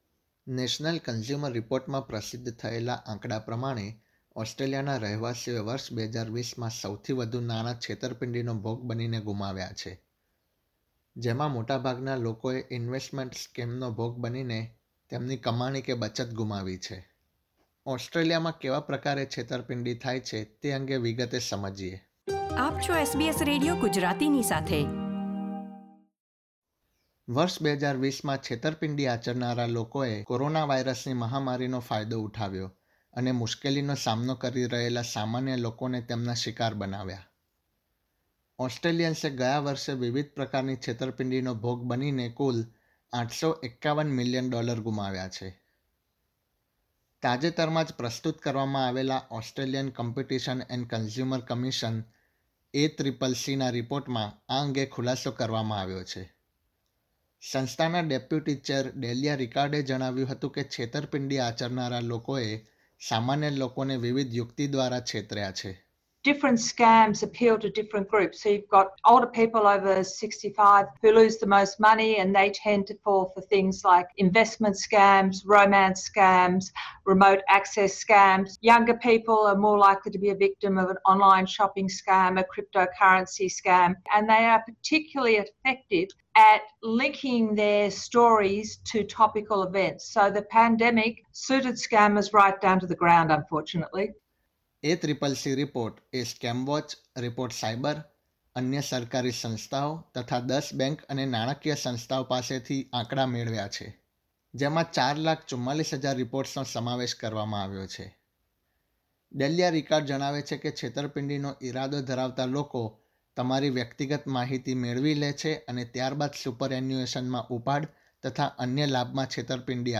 વર્ષ 2020માં કોરોનાવાઇરસની મહામારીની પરિસ્થિતીનો ફાયદો ઉઠાવીને છેતરપીંડી આચરનારા લોકોએ ઓસ્ટ્રેલિયાના રહેવાસીઓ સાથે નાણાકીય છેતરપીંડી કરી. જેમાં મોટાભાગે ઇન્વેસ્ટમેન્ટ તથા રોમાન્સ સાથે સંકળાયેલી છેતરપીંડીનું પ્રમાણ વધુ છે. દેશના રહેવાસીઓ સાથે કેવી રીતે છેતરપીંડી કરવામાં આવી તે વિશેનો અહેવાલ.